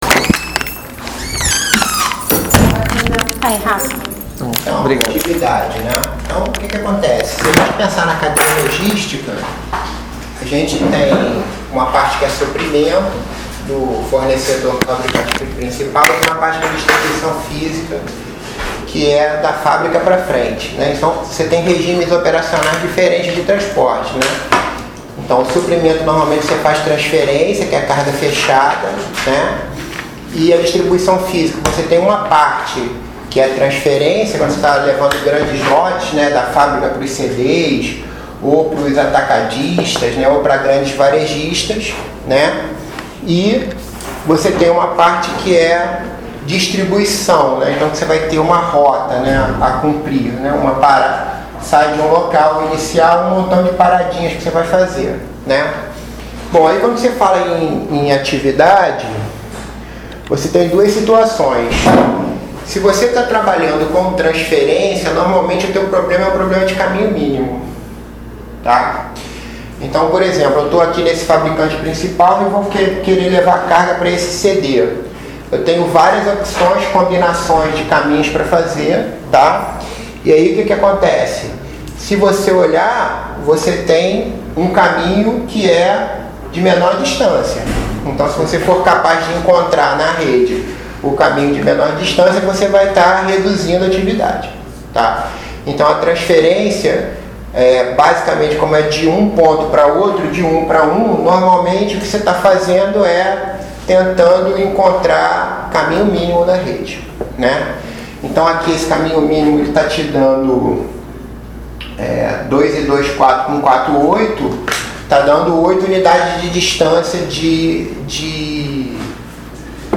aulas expositivas